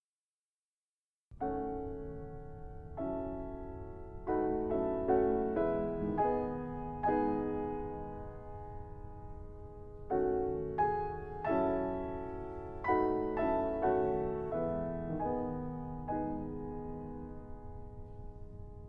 Ejemplo de tresillo en una pieza para piano